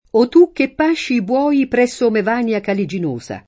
DOP: Dizionario di Ortografia e Pronunzia della lingua italiana
Mevania [lat. e it. mev # n L a ] top. stor.